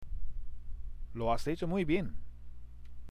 これも繋がって聞こえるフレーズでしたね。